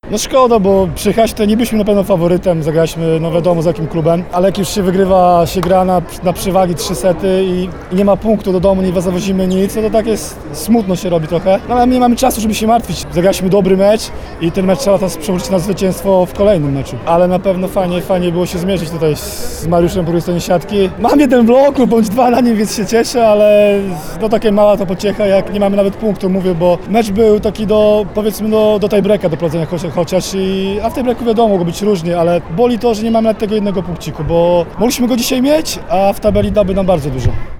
powiedział „na gorąco”, tuż po spotkaniu,